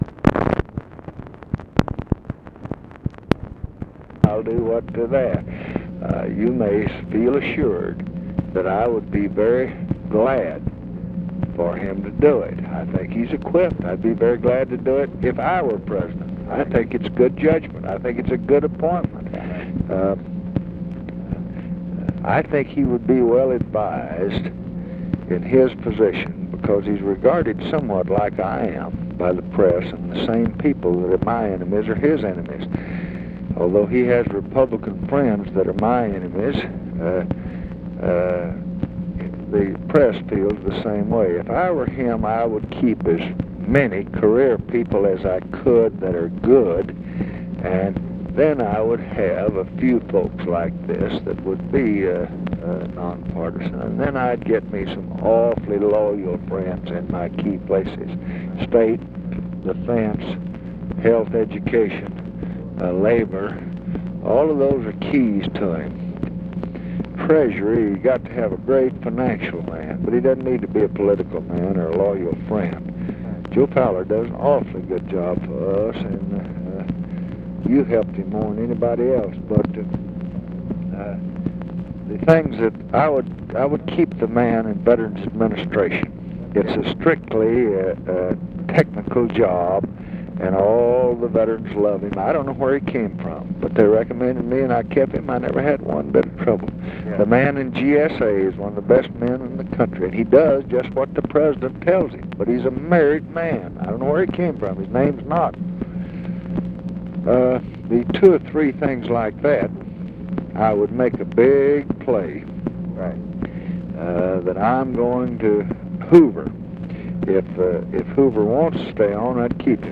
Conversation with GEORGE SMATHERS, November 21, 1968
Secret White House Tapes